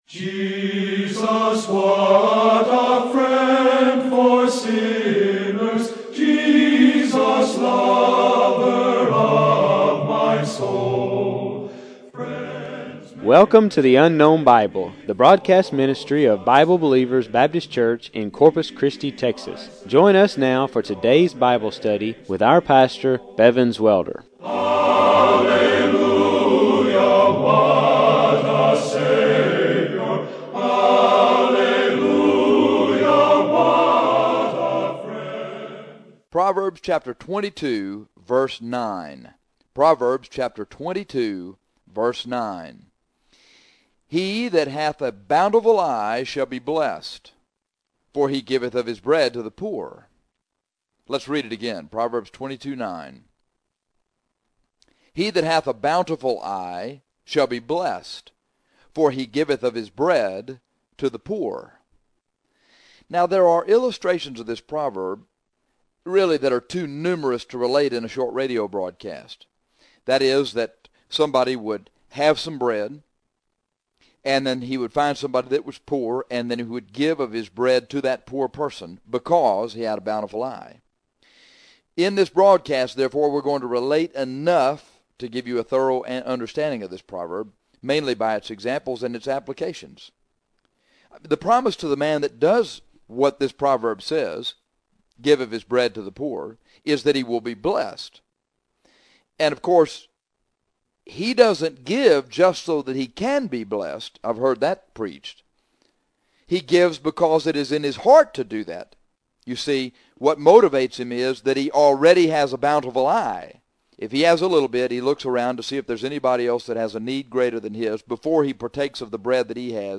There are illustrations of this proverb too numerous to relate in a short radio broadcast – but we will relate enough to give you a thorough understanding of the proverb – he giveth of his bread to the poor and he will be blessed for his bountiful eye – of course he doesn’t give just so that he can be blessed – he gives because it is in his heart to do so – he hath a bountiful eye!!